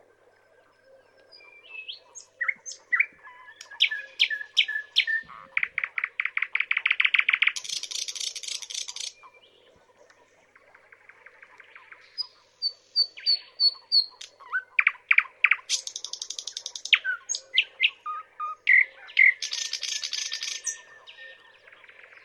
thrush nightingale song.wav
thrush_nightingale_song-2_xb7.mp3